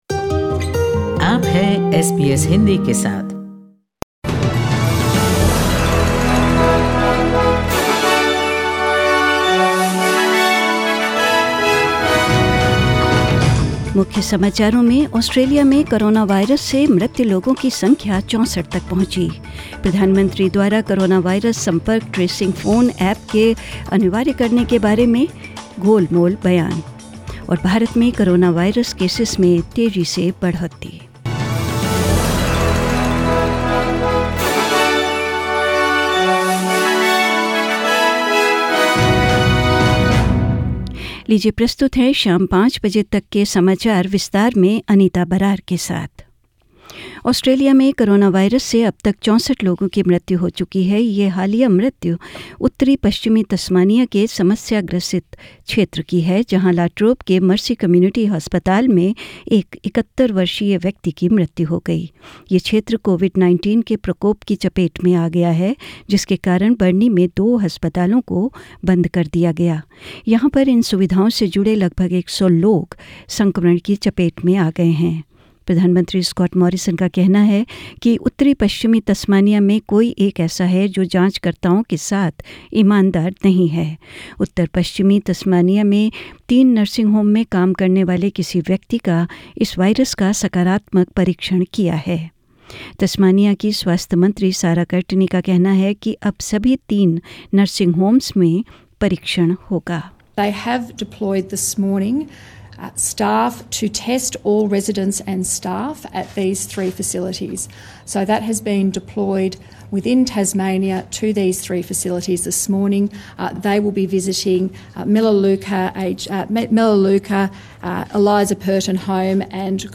Hindi News 17th April 2020